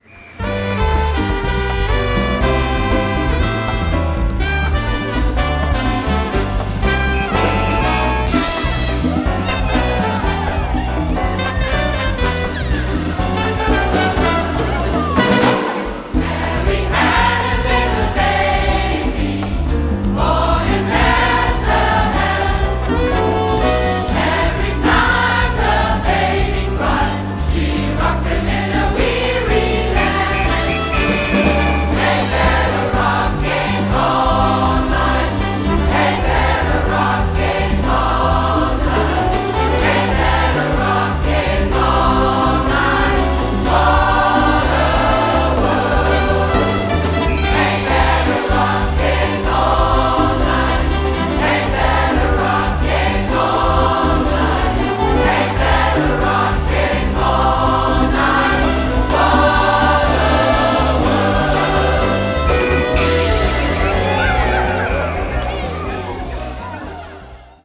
• SAB/SSA + Piano